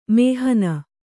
♪ mēhana